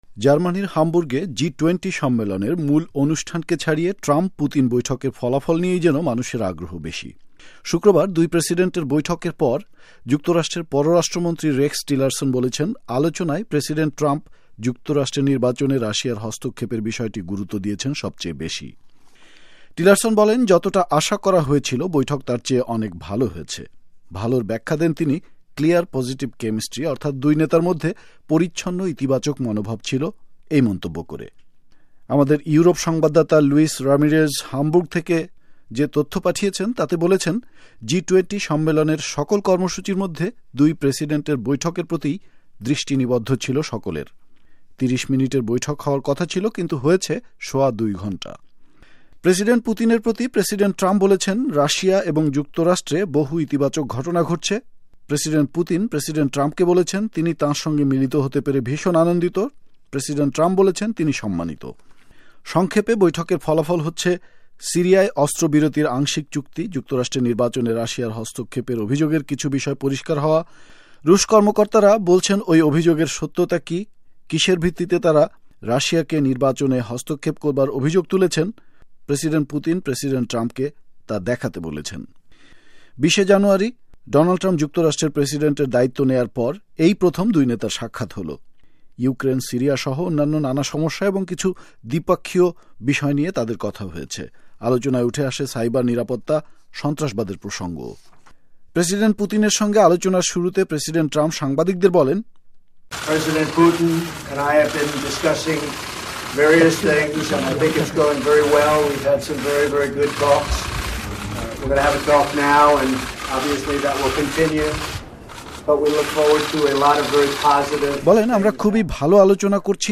জি -২০ সম্মেলন ও ট্রাম্প পুতিন বৈঠক সম্পর্কে বিশ্লেষণ করেন